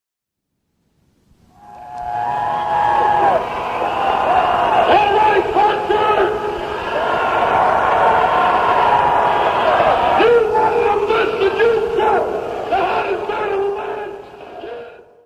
Venue: Hartford Civic Center
KISS performed in front of 9,150 fans performing 14 songs, with supporting act Sammy Hagar.